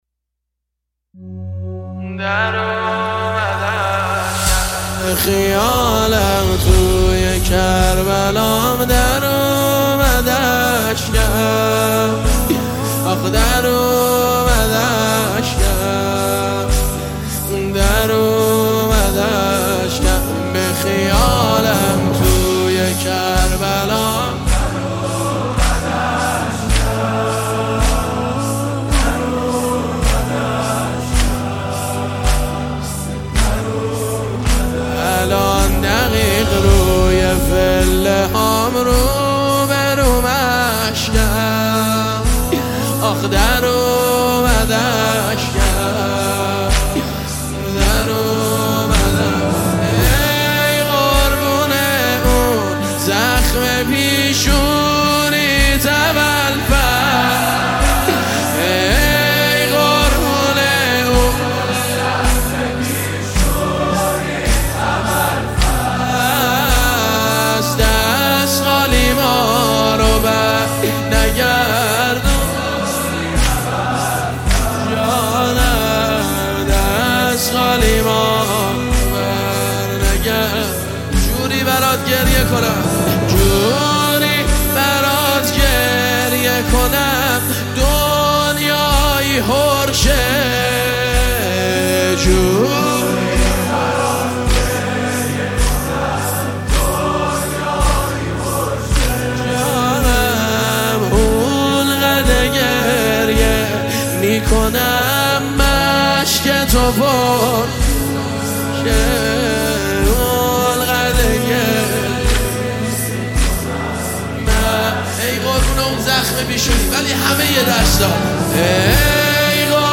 مناجات با حضرت ابالفضل (ع)